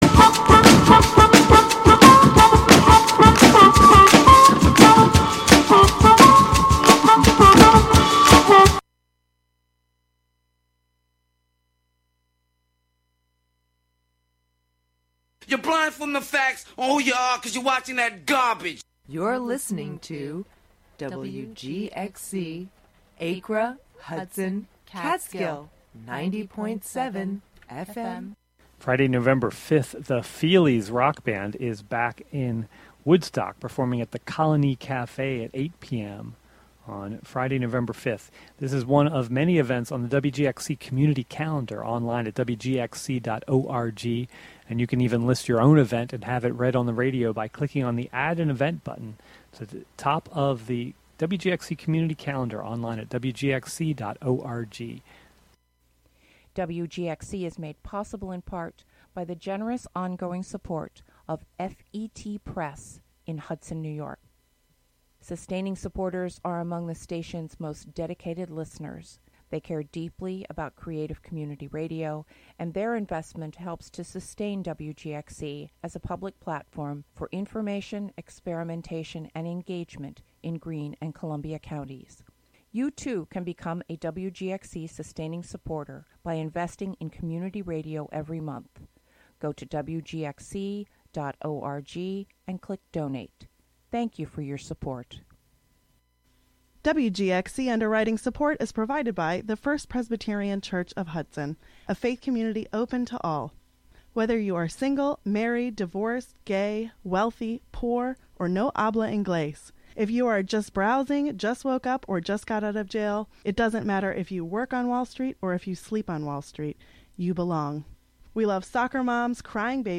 A radio series of proprioceptive exercises, interviews about practices of communication, and archival sound. A routine for warming up our means of communication. Presented monthly as a combination of live and prerecorded sessions.